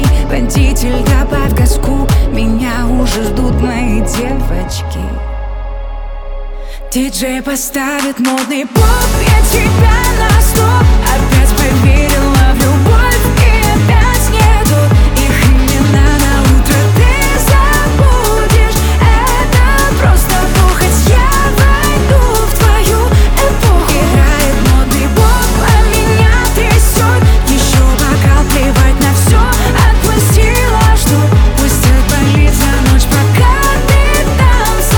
Pop Dance